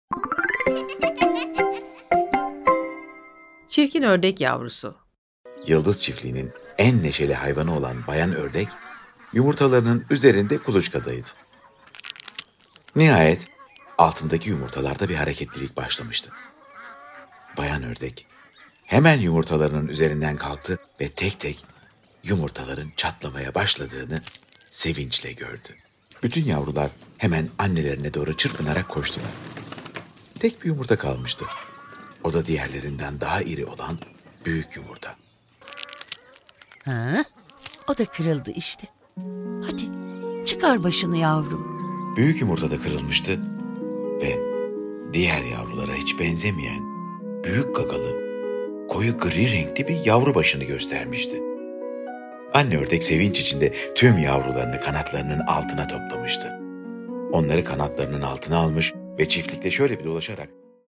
Çirkin Ördek Yavrusu Tiyatrosu